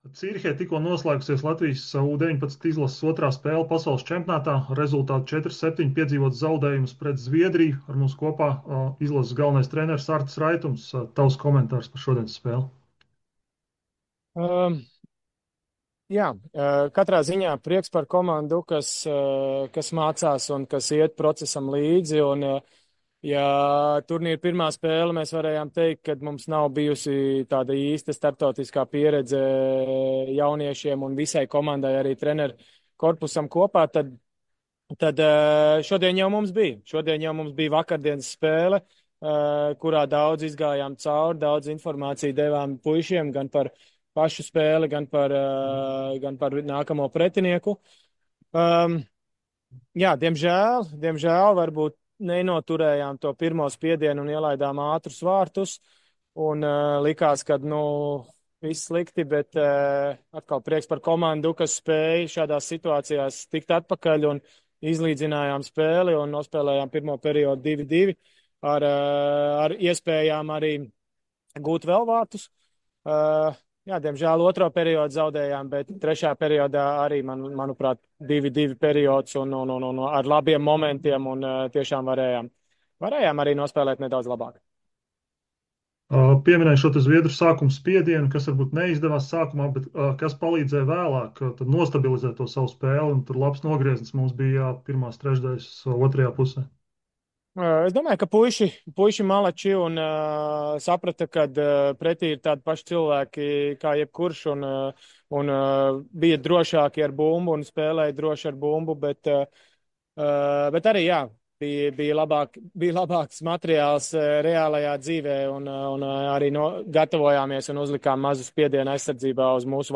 pēc spēles pret Zviedriju: